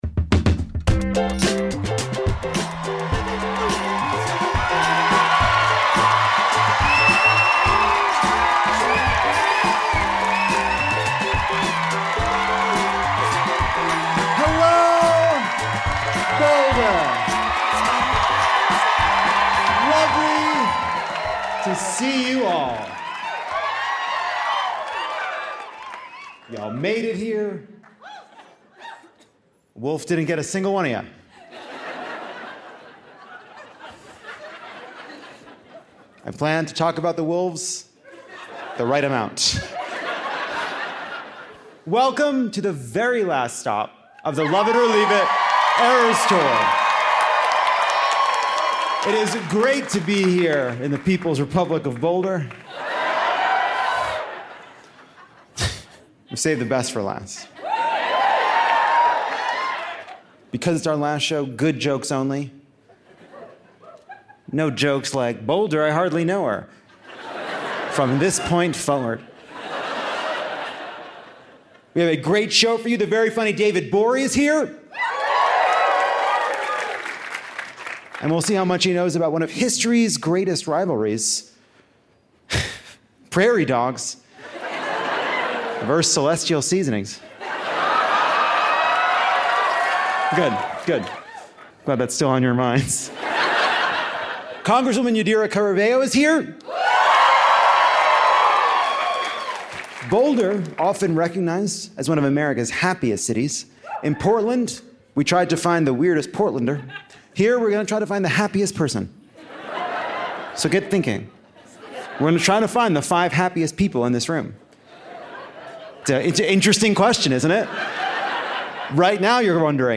Santos Sashays Away (Live from Boulder!)
Congresswoman Yadira Caraveo joins to talk about her race against anti-abortion extremists. We tried to find the happiest audience member in what’s said to be the happiest city but just found more weirdos.